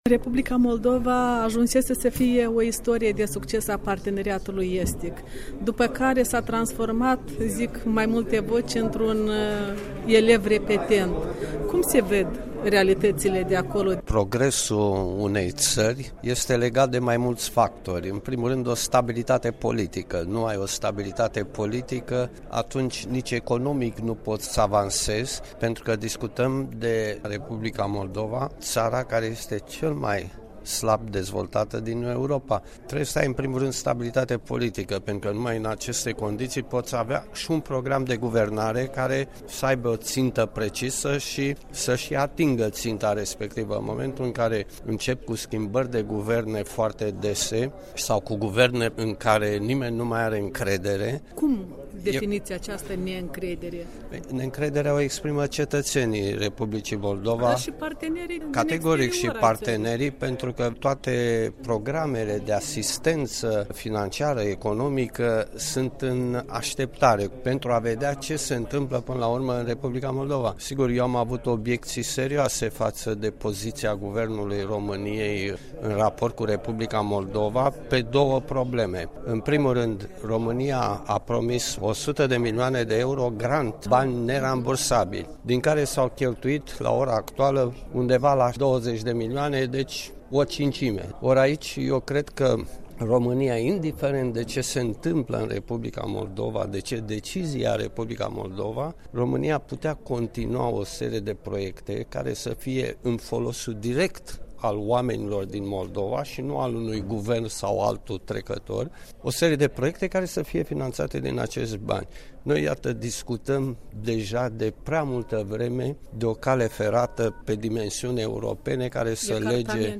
Fostul premier al României și membru al Parlamentului European răspunde întrebărilor Europei Libere.
Interviu cu Theodor Stolojan